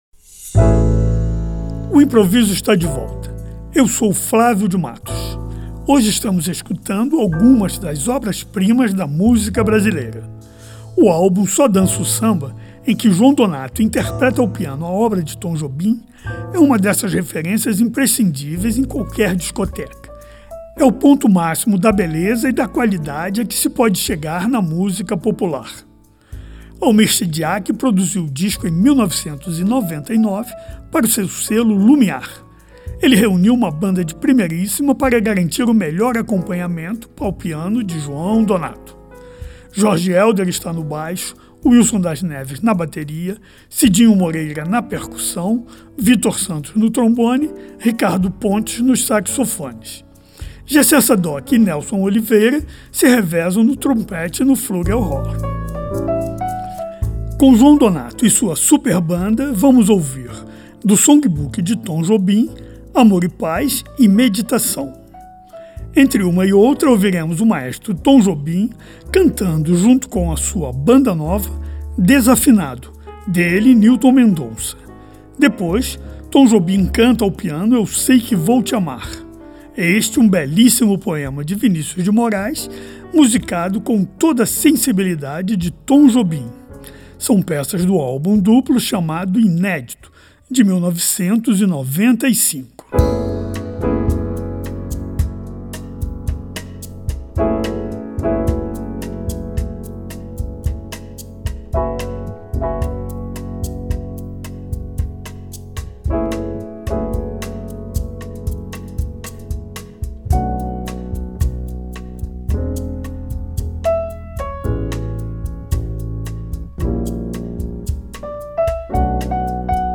Jazz Música Brasileira